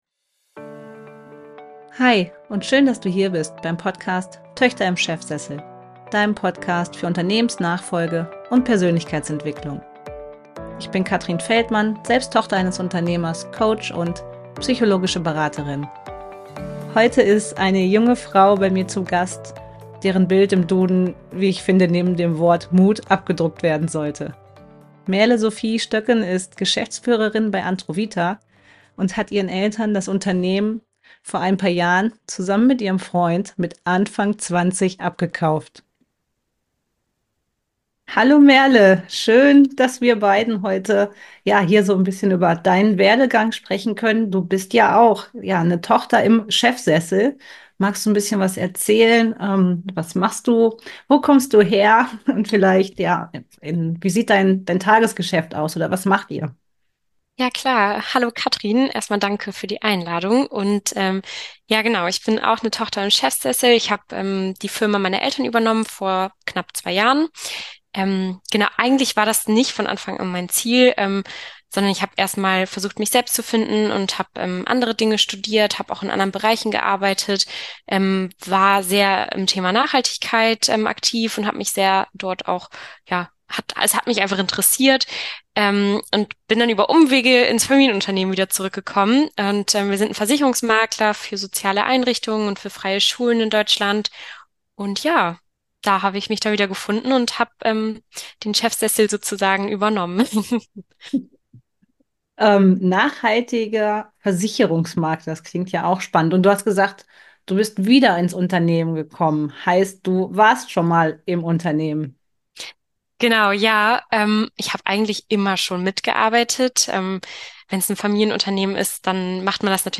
Heute ist eine junge Frau bei mir zu Gast, deren Bild im Duden neben dem Wort „Mut“ abgedruckt werden sollte, wie ich finde.